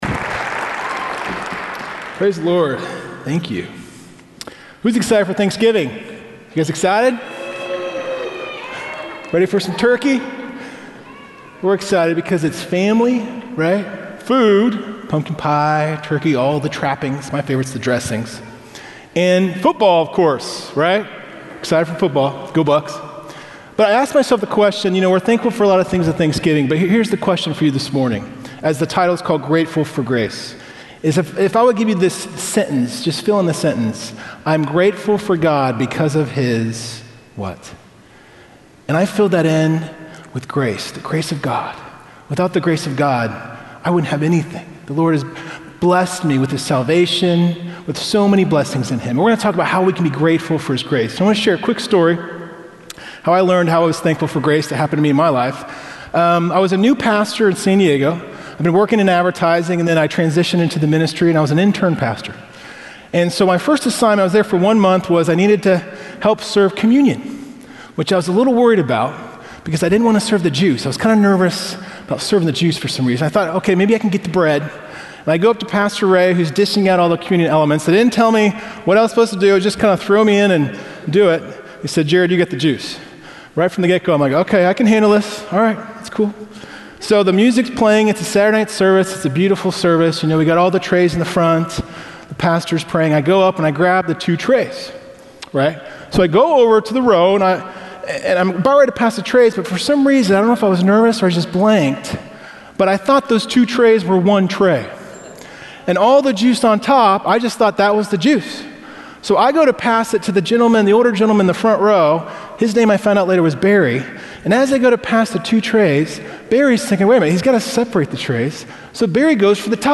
Check out Grateful for Grace, a sermon series at Fairhaven Church.